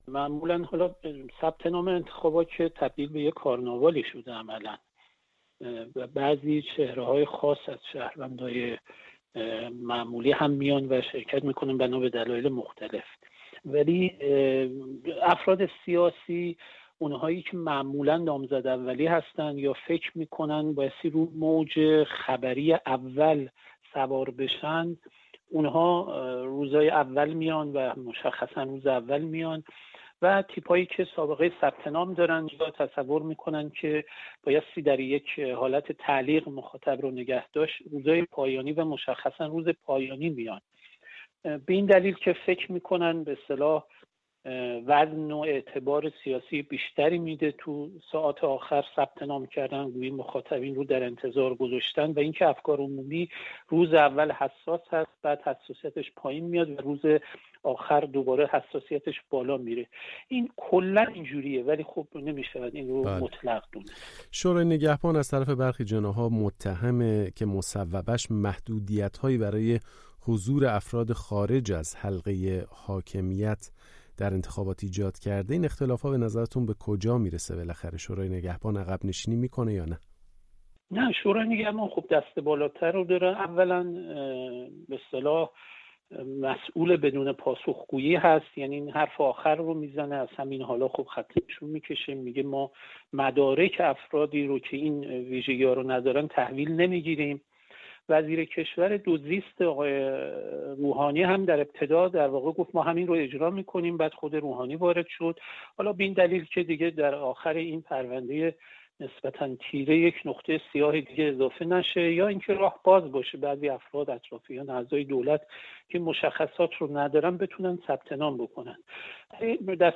گفت‌وگویی